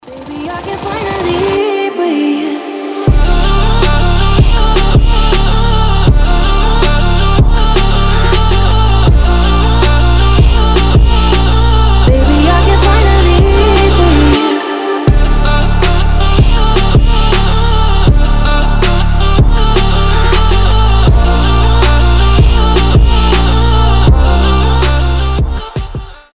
• Качество: 320, Stereo
атмосферные
женский голос
басы